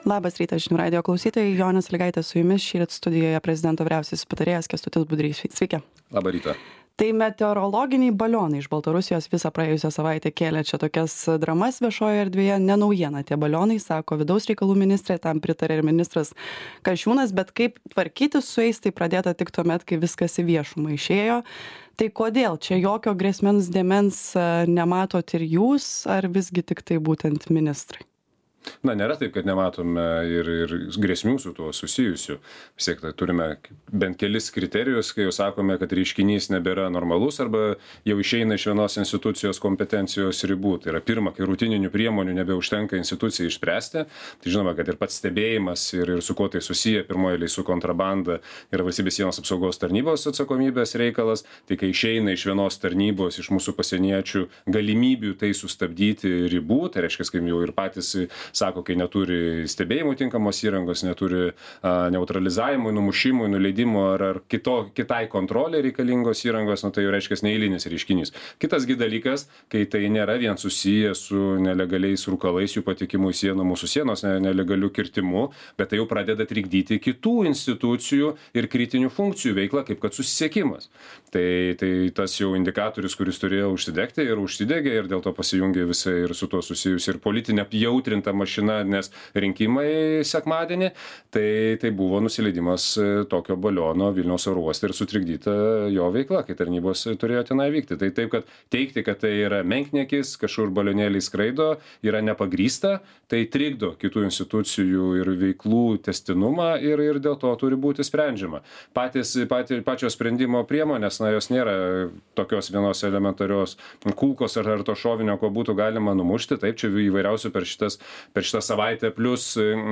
Pokalbis su prezidento vyriausiuoju patarėju Kęstučiu Budriu.